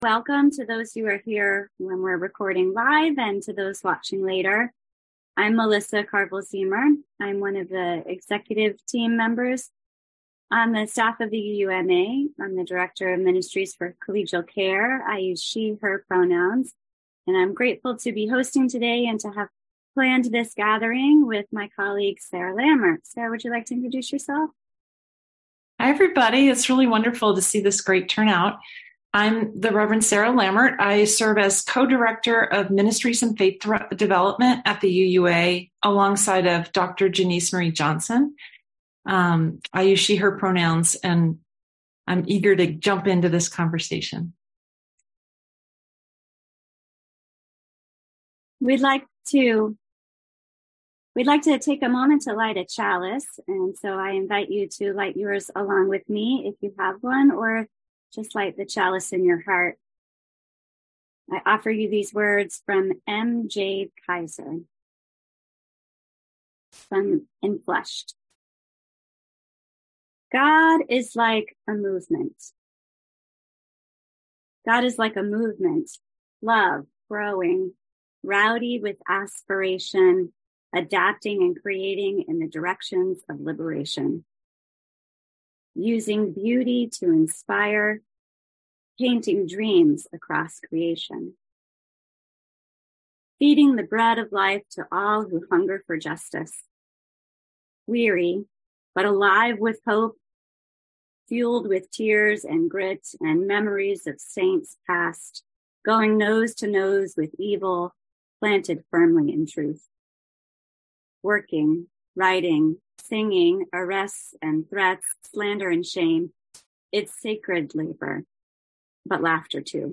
June 2 Episode: Trends in Parish Ministry: Full Conversation This week we are presenting the full presentation from the recent Trends in Parish Ministry Collegial Conversation as our podcast episode .